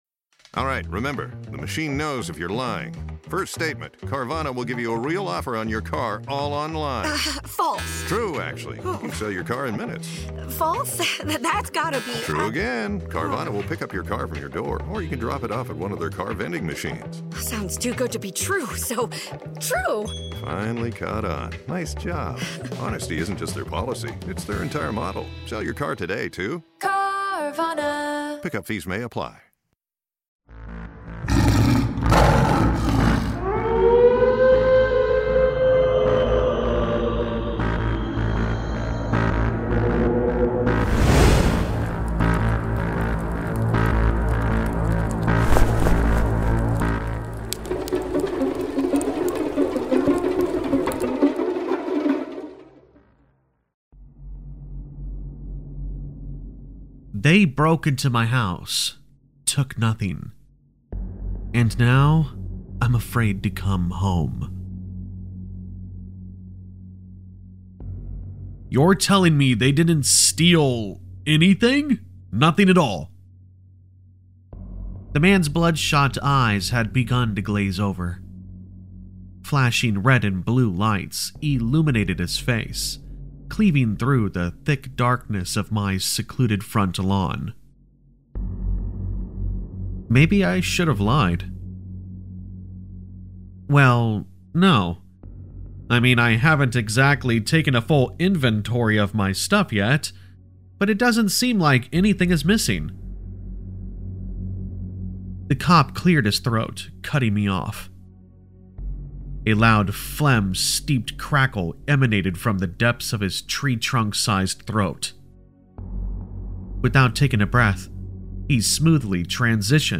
Told in gripping narrator style, this haunting tale pulls you into a world of shadowy figures, unexplained intrusions, and the creeping realization that sometimes the scariest part isn’t what they take… it’s what they leave behind.